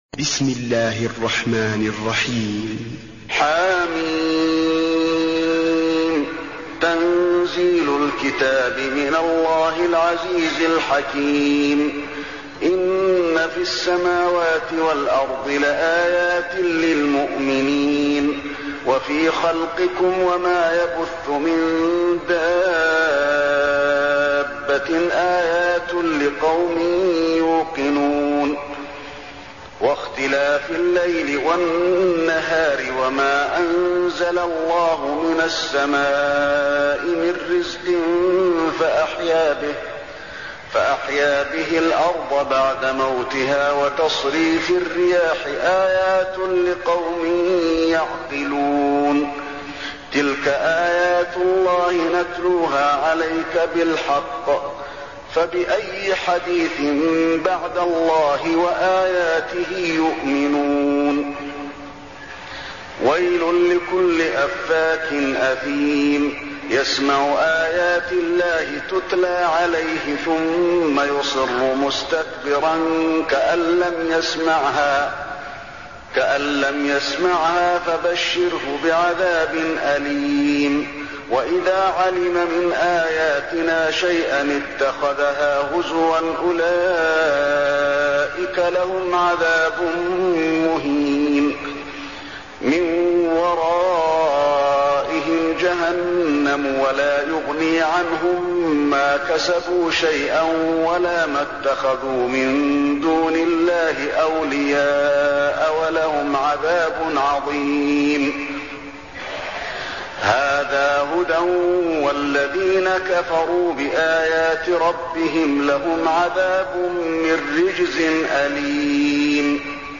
المكان: المسجد النبوي الجاثية